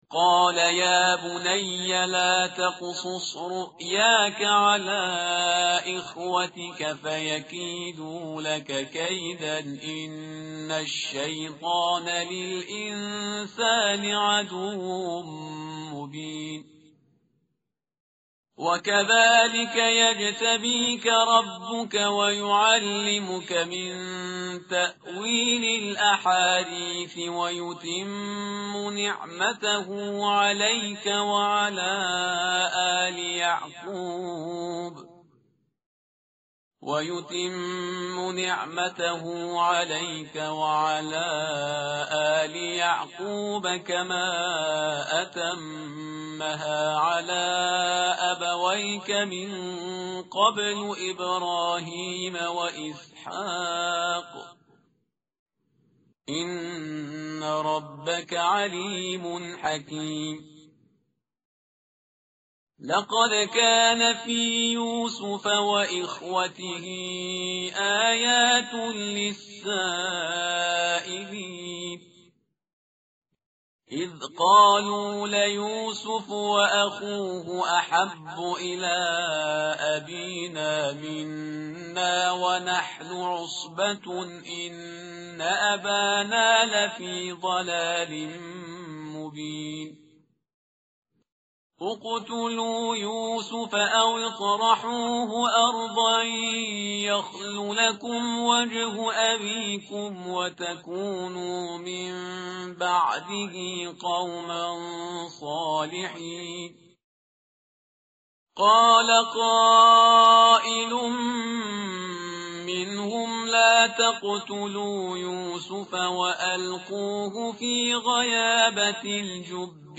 متن قرآن همراه باتلاوت قرآن و ترجمه
tartil_parhizgar_page_236.mp3